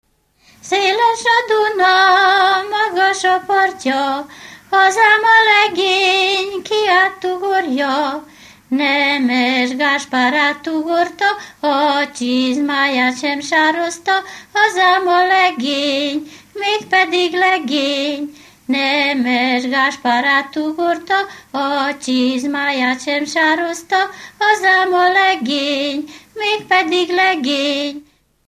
Alföld - Bács-Bodrog vm. - Gombos
Műfaj: Párosító
Stílus: 7. Régies kisambitusú dallamok